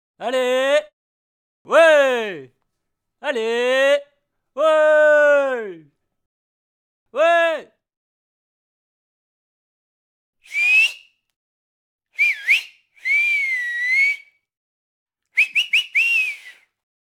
CHARLIE PUBLIC JEUNE HOMME ENCOURAGE SIFFLEMENT